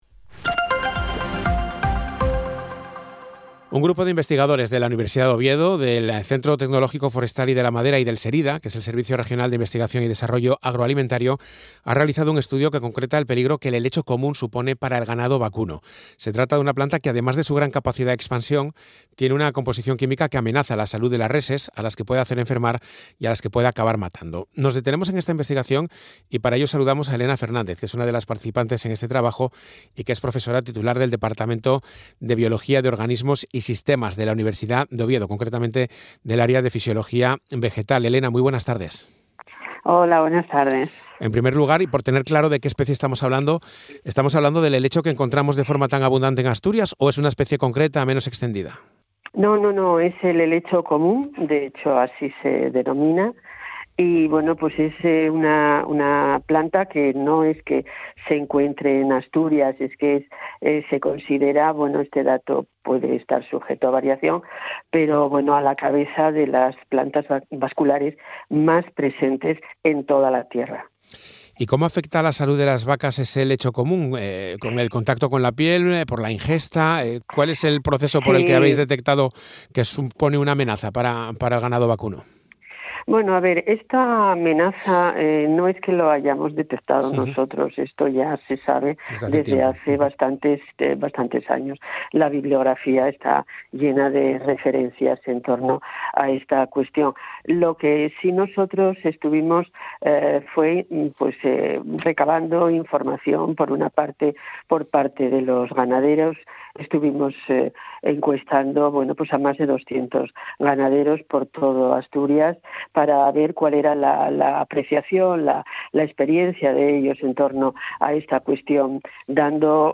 Entrevista_Radio